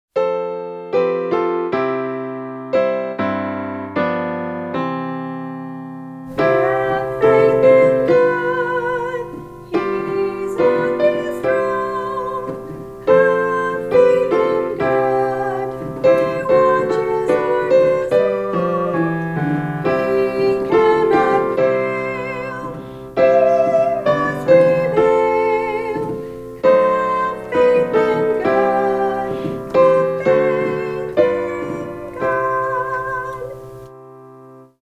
Practice Track 2 – use this to record yourself singing the Alto line.